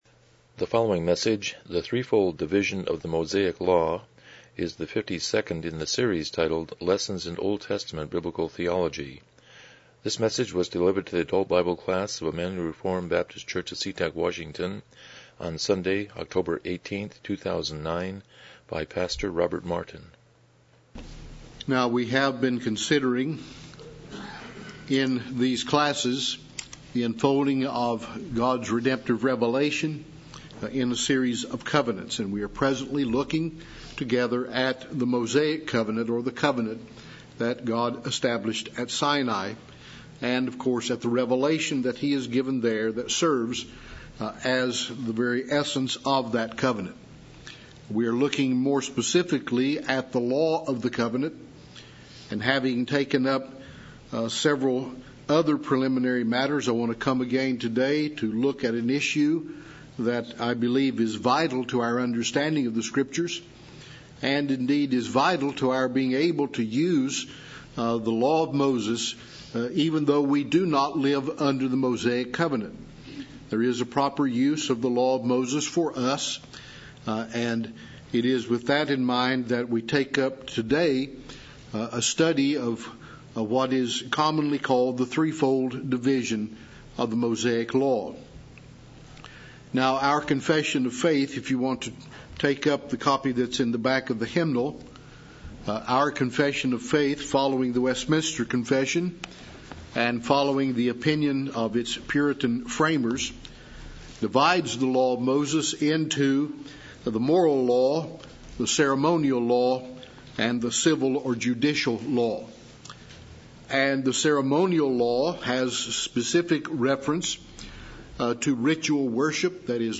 Lessons in OT Biblical Theology Service Type: Sunday School « 77 Chapter 14.2